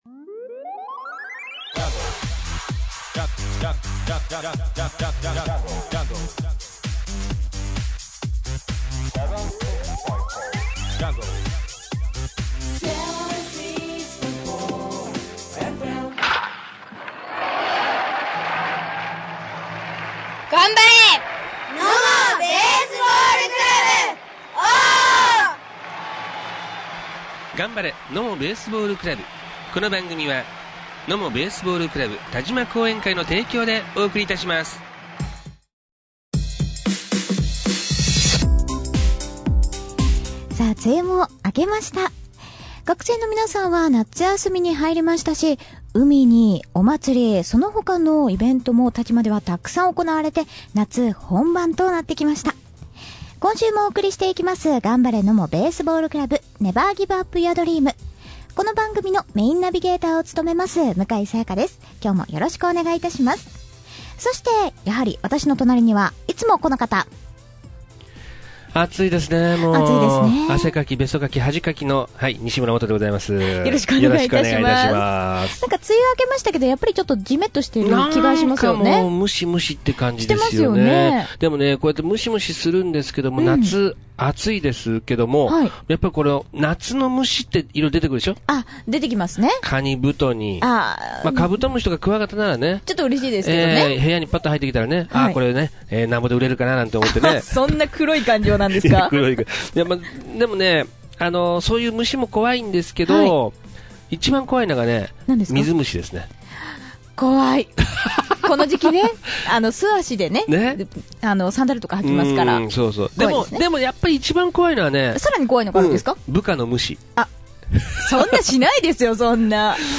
放送日：第17回 7/23（水） 7月21日に開催されました「城崎ふるさとまつり」の下駄ラックアウトのサポートとして、参加されました。 参加された選手の方々にお話をお伺いしました。